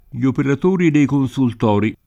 l’ operat1ri dei konSult0ri]